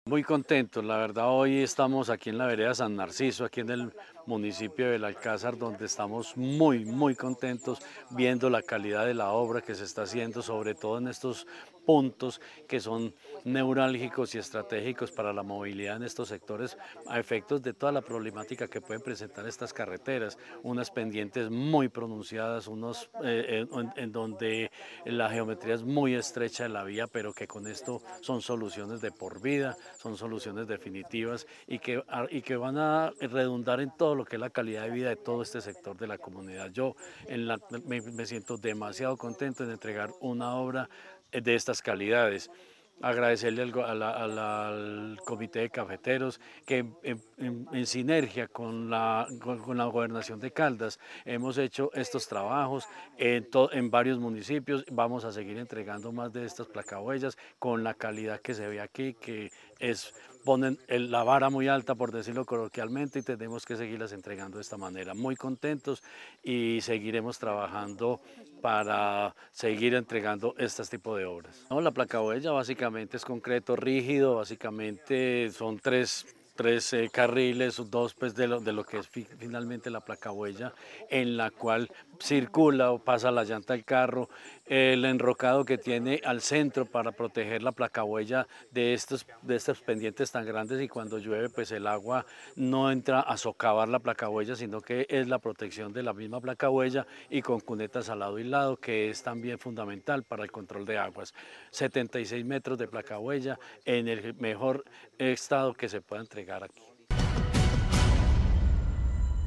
Jorge Ricardo Gutiérrez Cardona, secretario de Infraestructura de Caldas.